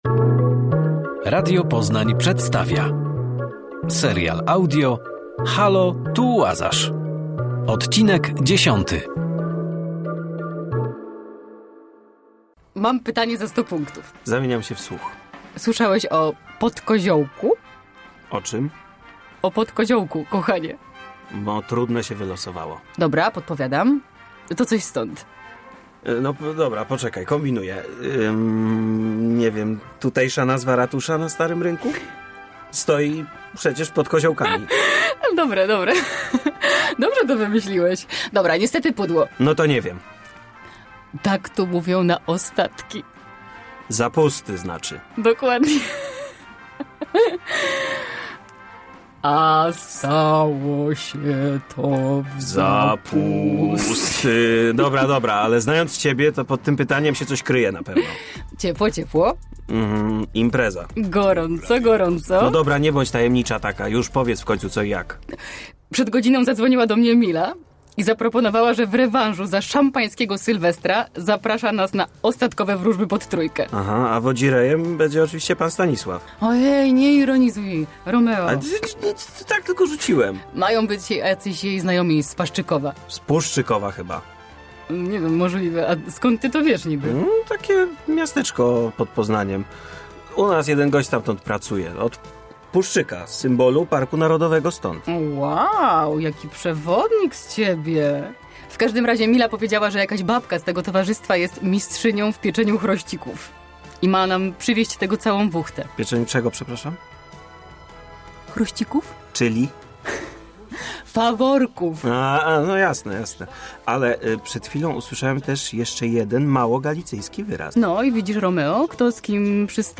Serial audio